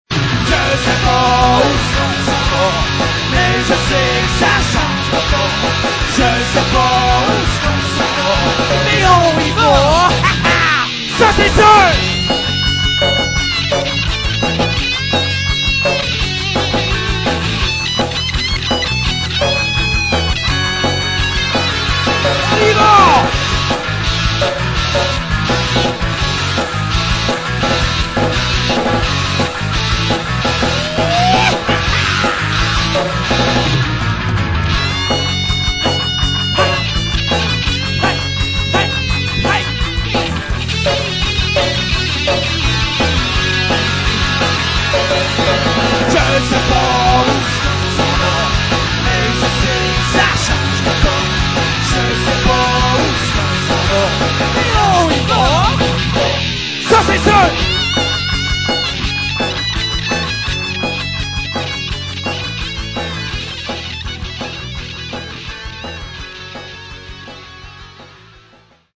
guitare
basse
batterie et voix